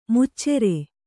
♪ muccere